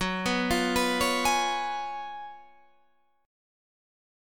GbM7sus2sus4 chord